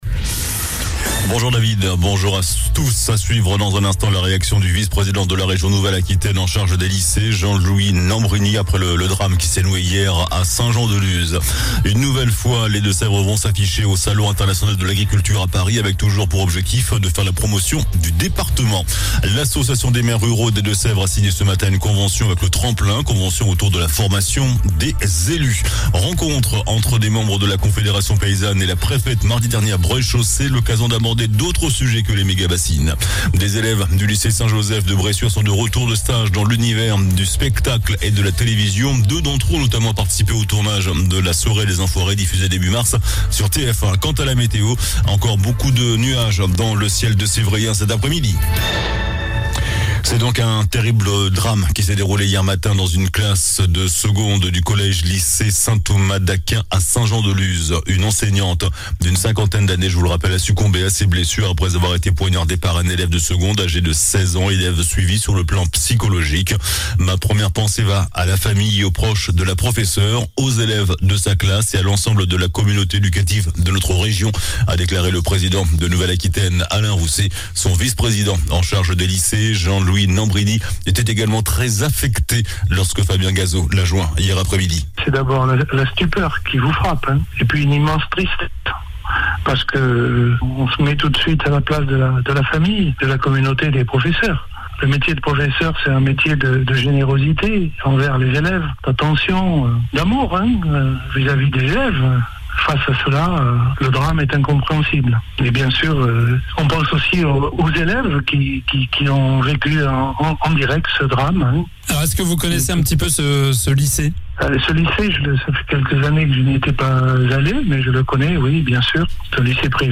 JOURNAL DU JEUDI 23 FEVRIER ( MIDI )